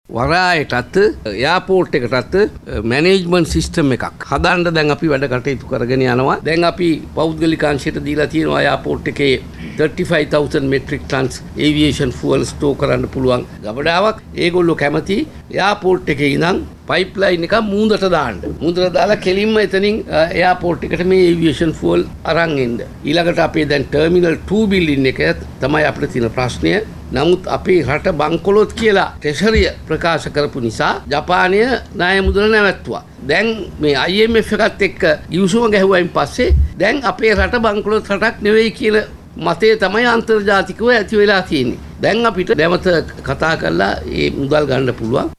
මේ එහිදී අදහස් පල කල වරාය, නාවික හා ගුවන් සේවා අමාත්‍ය නිමල් සිරිපාල ද සිල්වා මහතා